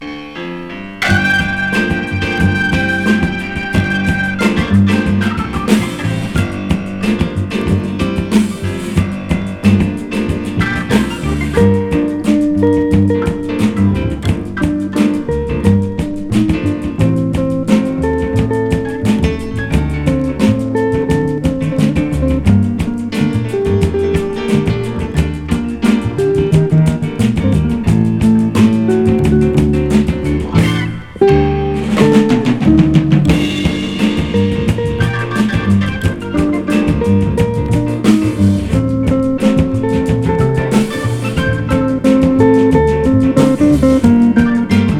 ギター、オルガンの他、フルート、ベース、ドラムといった編成。"
グルーヴィーさ有り、ドラマチックさ有り、通して抑揚の効いた楽曲と演奏に思わず情景が浮かびます。